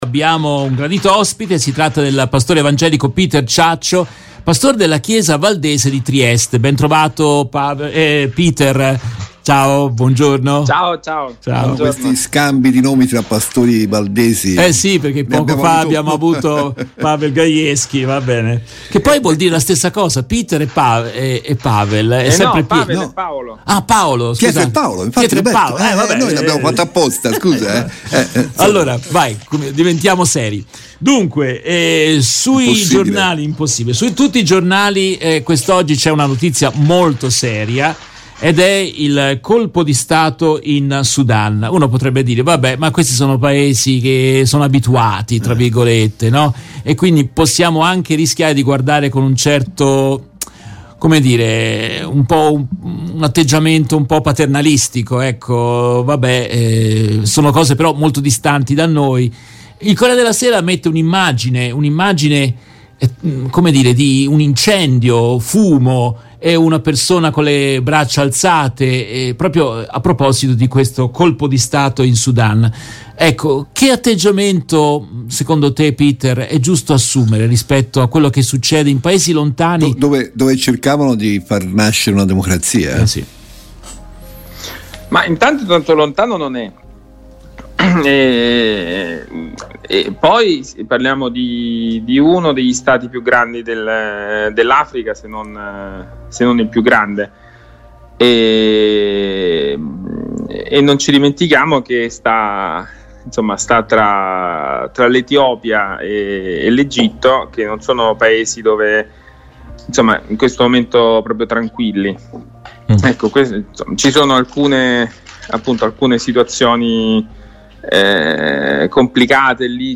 In questa registrazione tratta dalla diretta RVS del 26 ottobre 2021